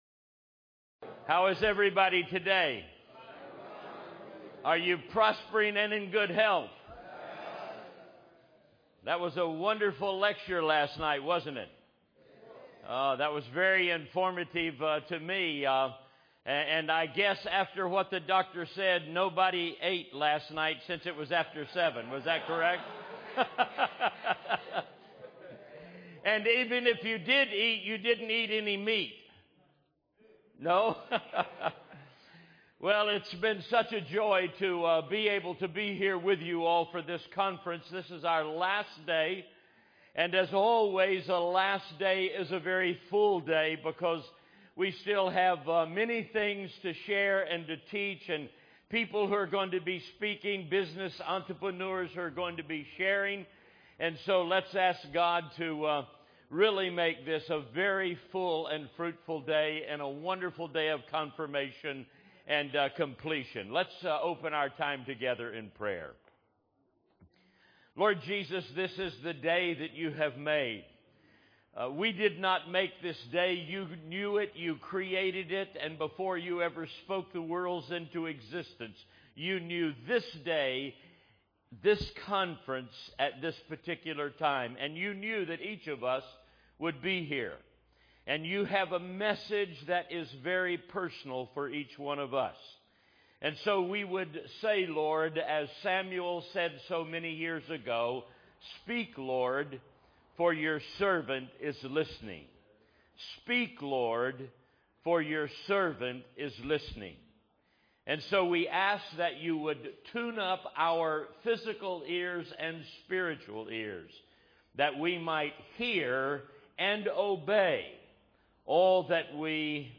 This message was delivered to a gathering of pastors in Ghana, Africa in August of 2009.